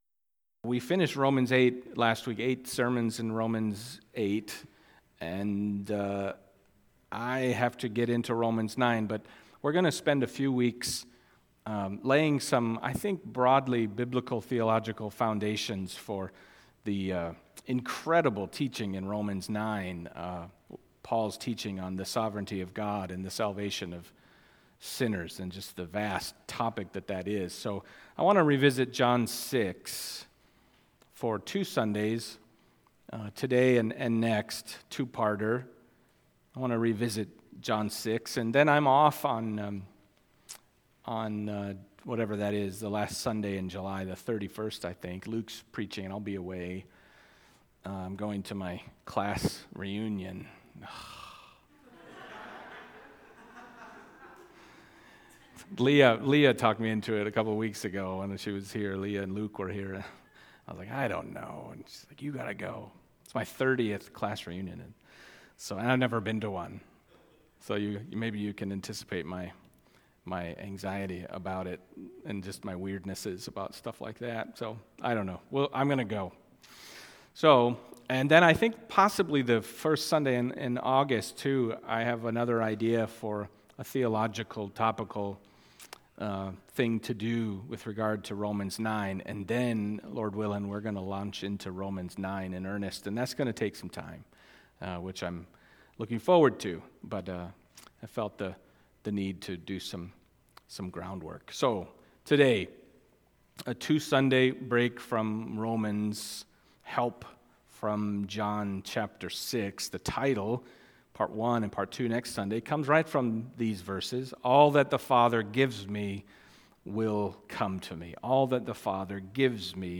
Passage: John 6:30-40 Service Type: Sunday Morning